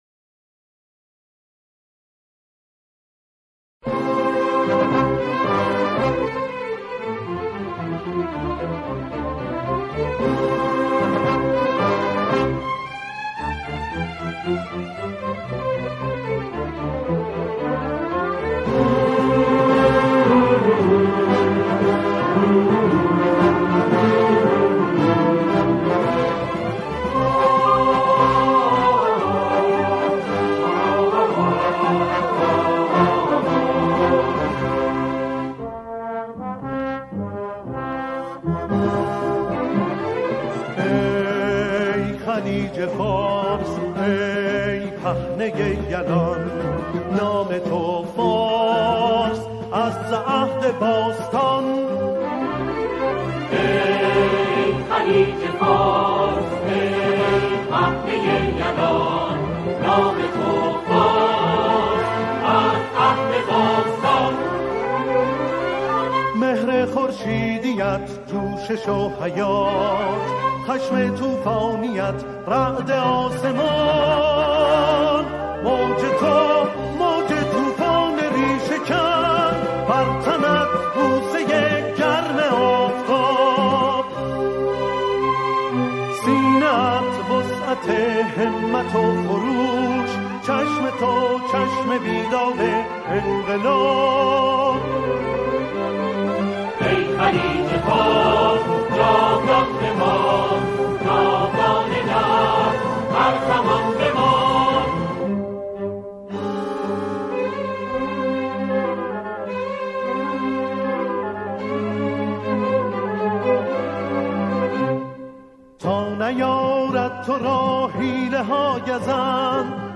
یک موسیقی نظامی